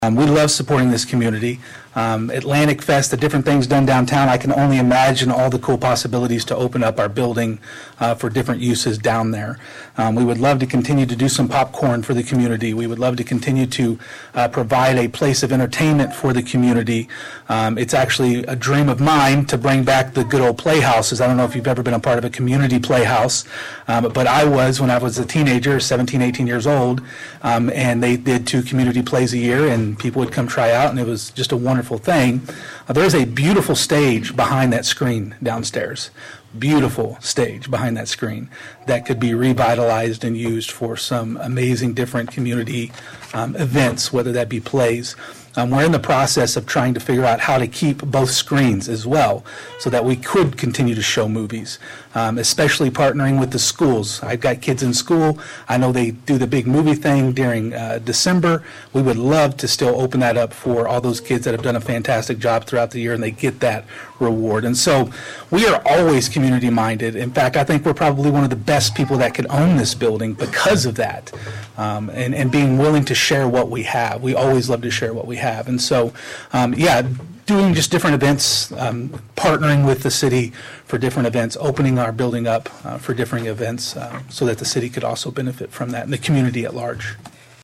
The Board consisting of Chairperson Melissa Ihnen, Vice-Chair, Alexsis Fleener, Brian Ruge, and Lloyd Munson, held a public hearing on Thursday night in front of a large crowd in the City Council Chambers.